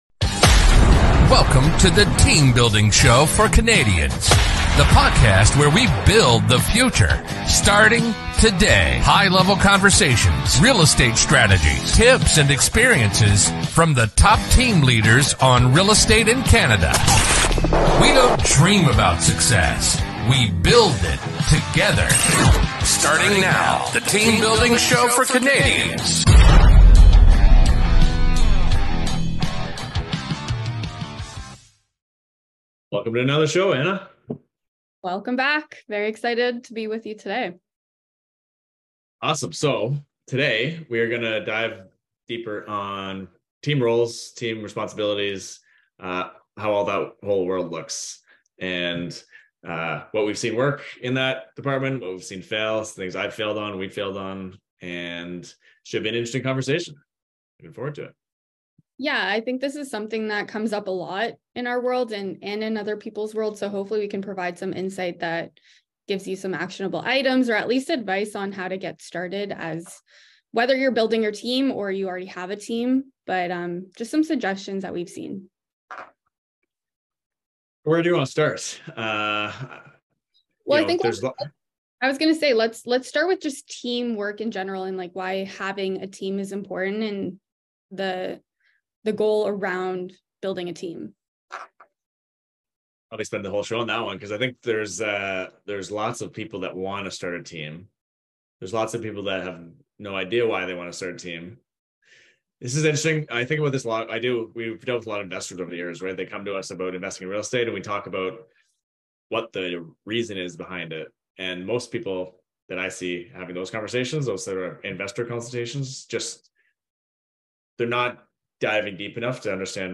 Welcome to the third episode of The Team Building Show. In this engaging conversation, we aim to provide valuable insights and actionable items for individuals who are either in the process of building a team or already have a team in place. When it comes to team roles and responsibilities, we understand the significance of clear and defined roles within an organization.